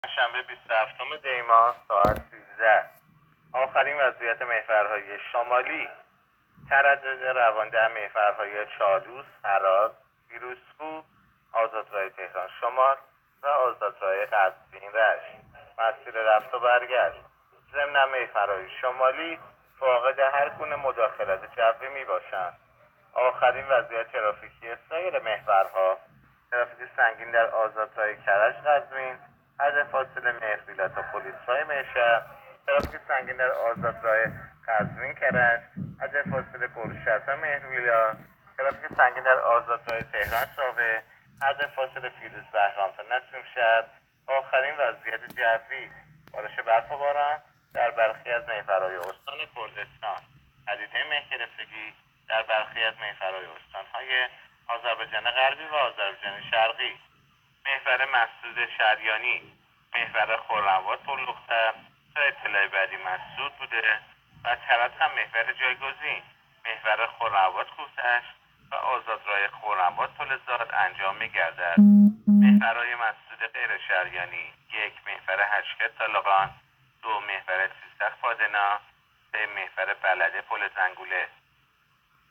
گزارش رادیو اینترنتی از آخرین وضعیت ترافیکی جاده‌ها تا ساعت ۱۳ بیست و هفتم دی؛